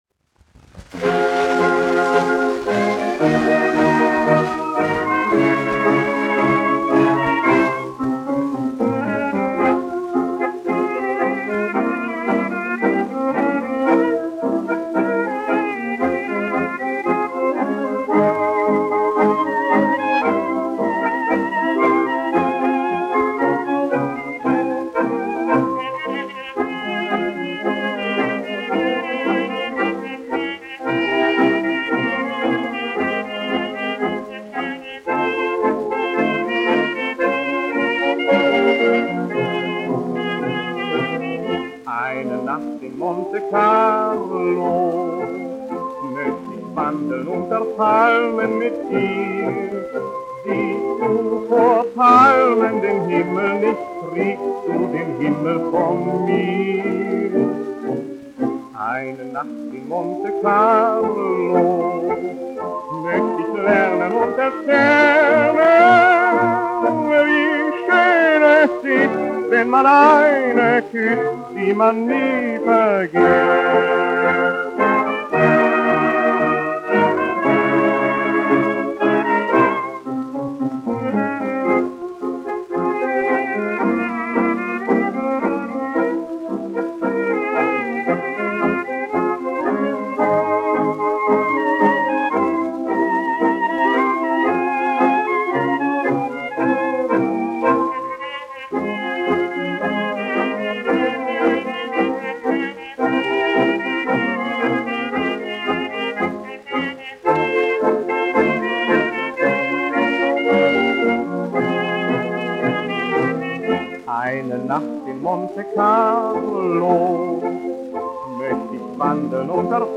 1 skpl. : analogs, 78 apgr/min, mono ; 25 cm
Populārā mūzika